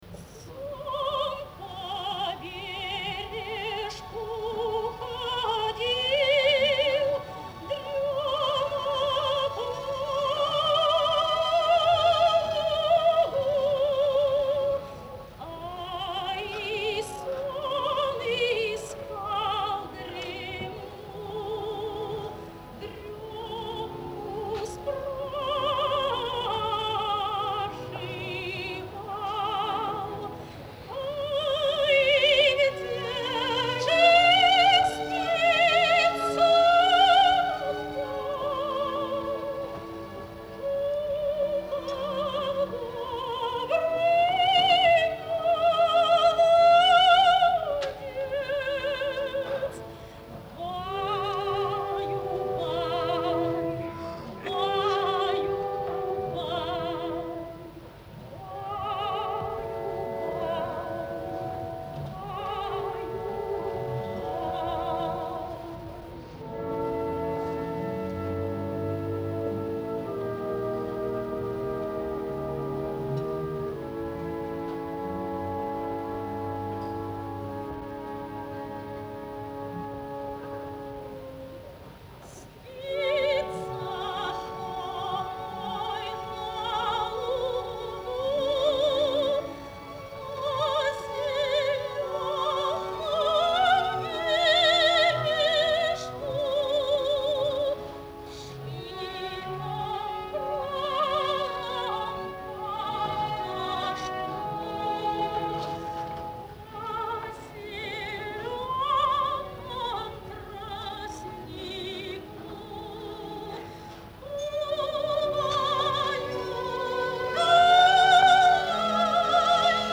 Жанр: Opera
В исполнении отечественных певиц.